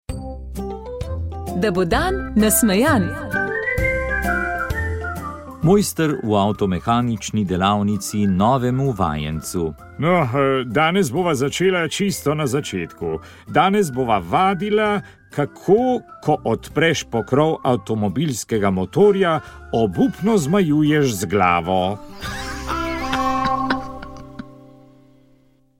Duhovni nagovor
Murskosoboški nadškof Peter Štumpf je v tokratnem nagovoru razmišljal o pomenu relikvij.